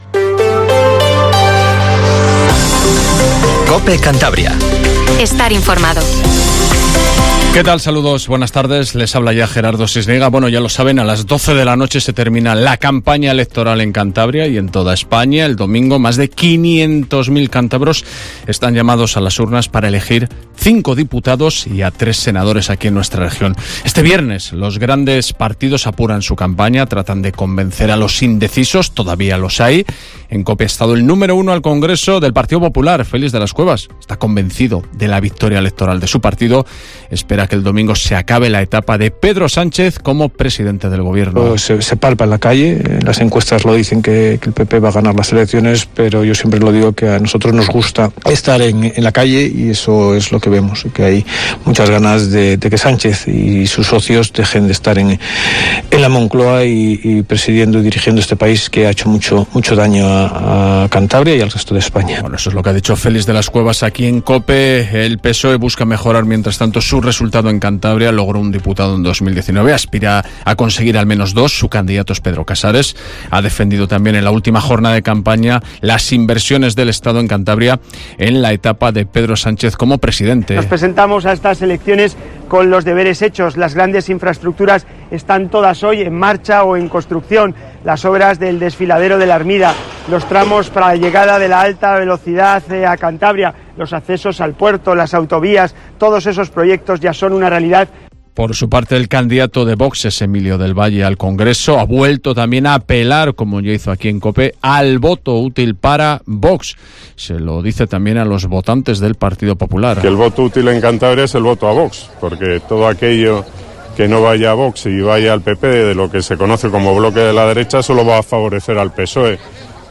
Informatiivo Mediodía COPE CANTABRIA